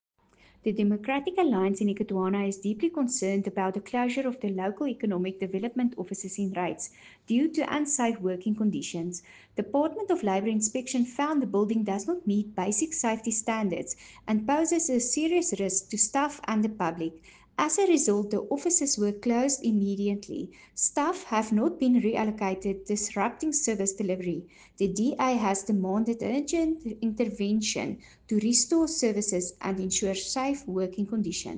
Afrikaans soundbites by Cllr Anelia Smit and